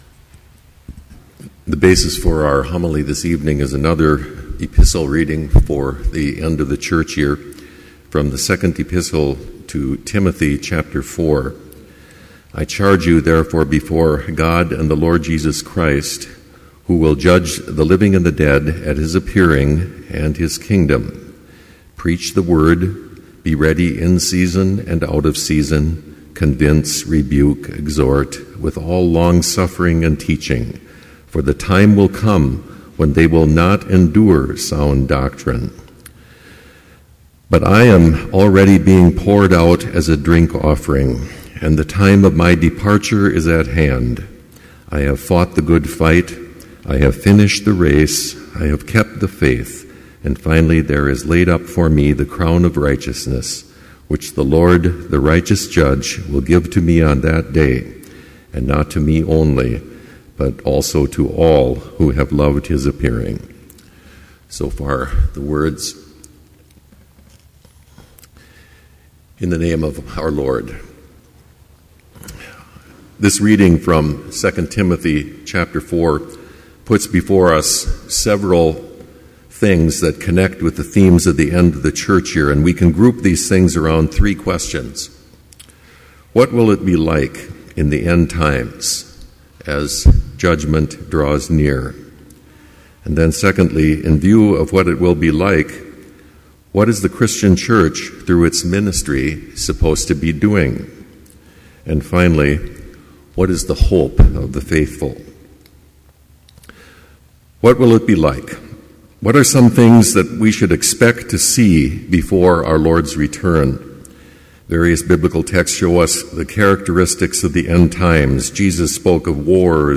Complete service audio for Evening Vespers - November 14, 2012